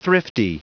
Prononciation du mot thrifty en anglais (fichier audio)
Prononciation du mot : thrifty